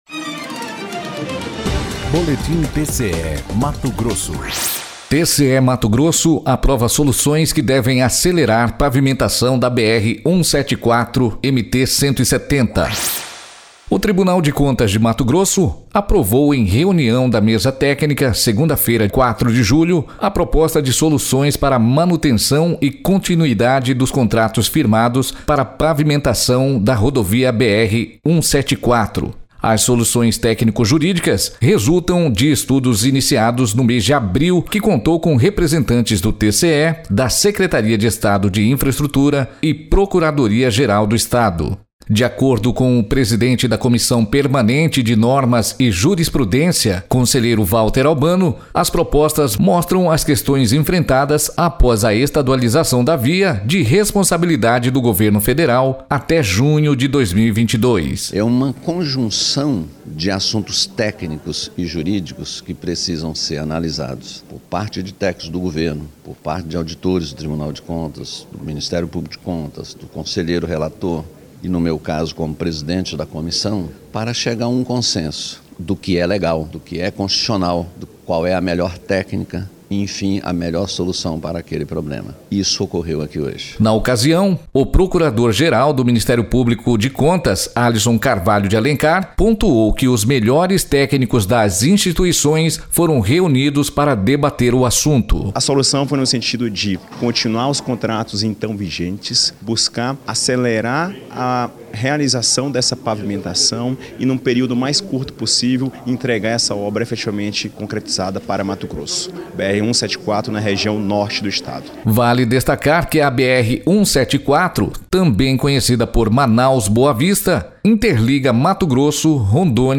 Sonora: Valter Albano – conselheiro presidente da Comissão Permanente de Normas e Jurisprudência
Sonora: Alisson Carvalho de Alencar - procurador-geral do MPC
Sonora: Marcelo de Oliveira e Silva – secretário de Infraestrutura de M T